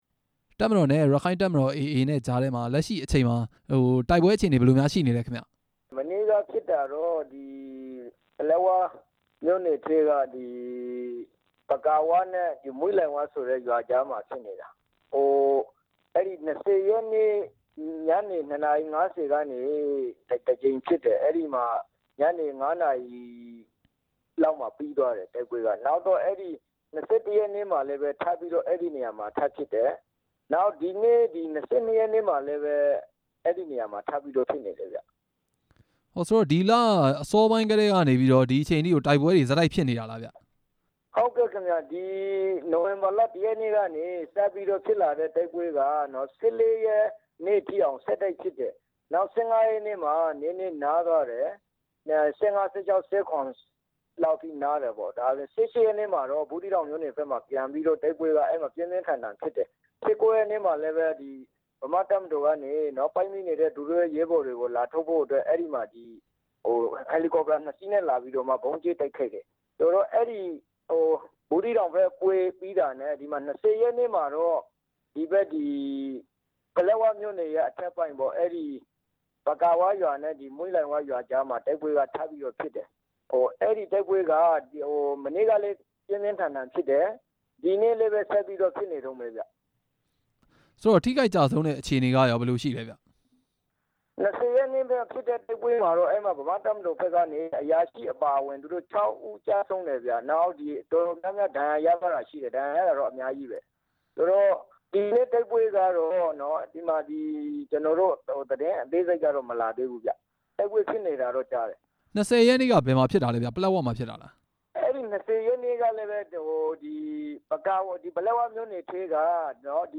အစိုးရတပ်မတော်နဲ့ ရက္ခိုင့်တပ်မတော် တိုက်ပွဲအကြောင်း မေးမြန်းချက်